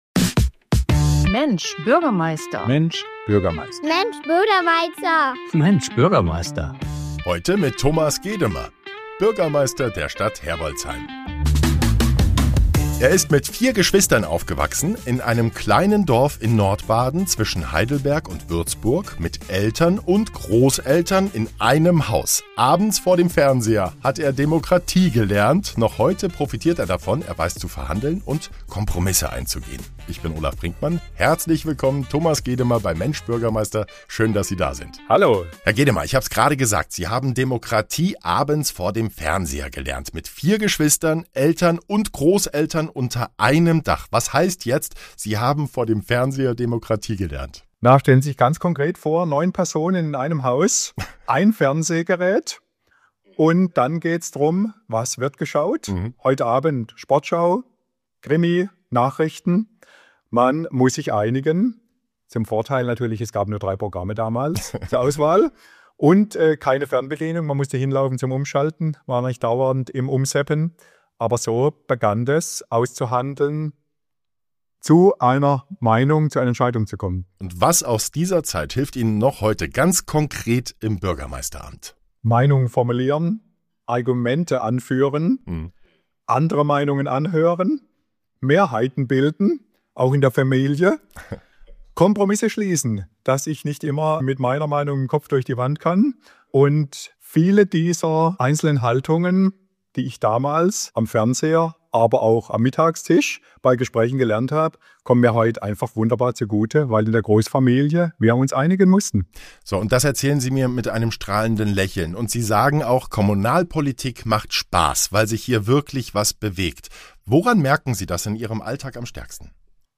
In dieser Folge von „Mensch, Bürgermeister!“ ist Thomas Gedemer zu Gast, Bürgermeister der Stadt Herbolzheim.